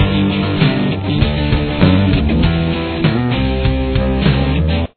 Verse
Here’s what the guitar and bass sound like together: